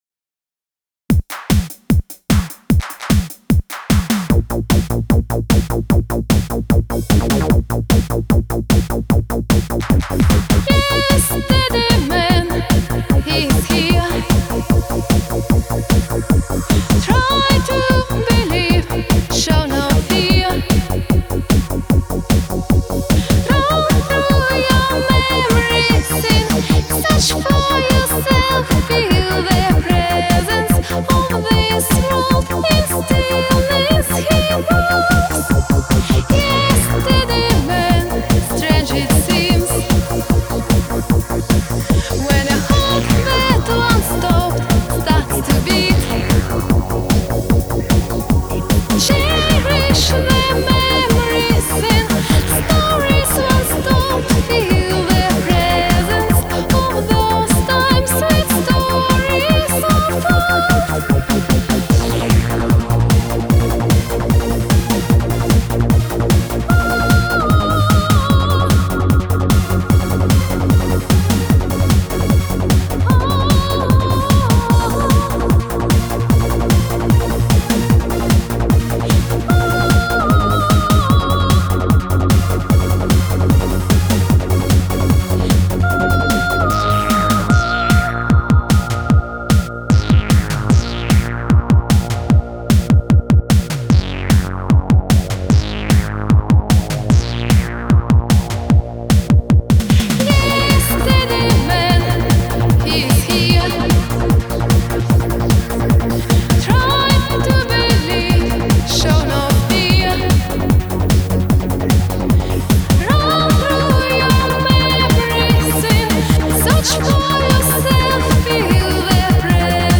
Trance Cover